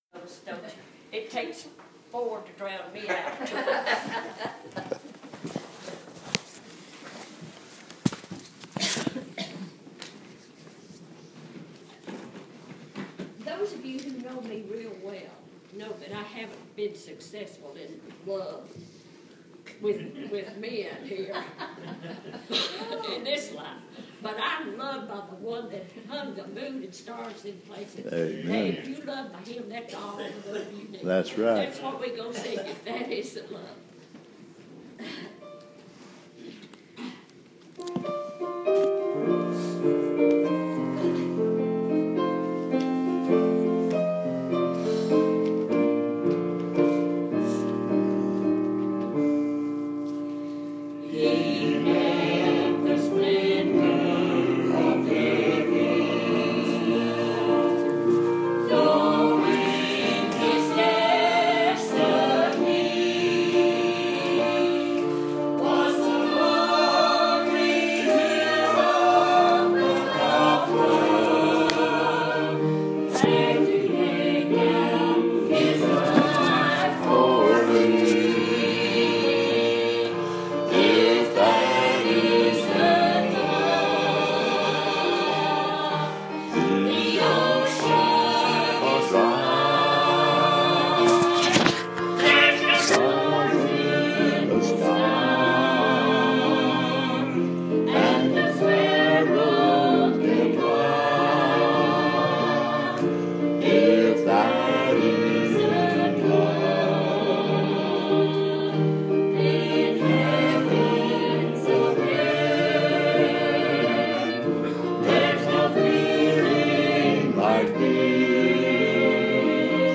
Preached at Riverview Baptist 798 Santa Fe Pike, Columbia TN May 21 2017